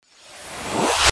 FX-713-WIPE
FX-713-WIPE.mp3